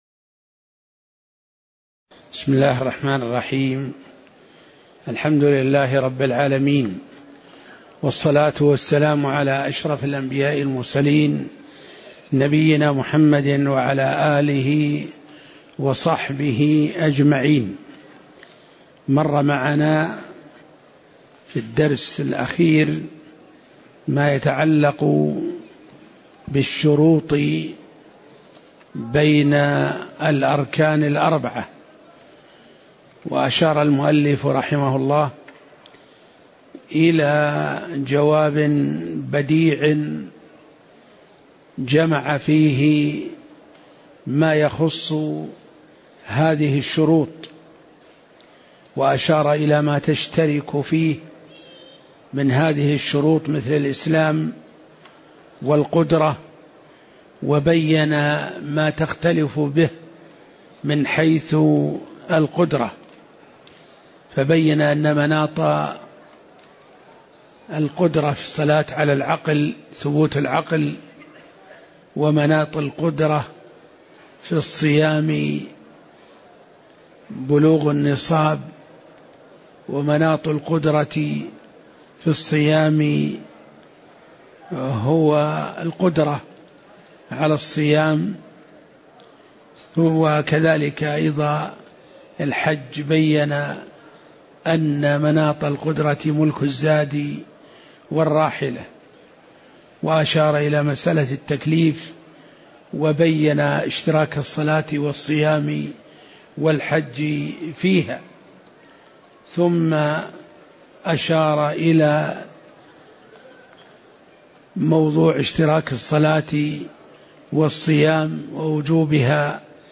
تاريخ النشر ١٦ شوال ١٤٣٨ هـ المكان: المسجد النبوي الشيخ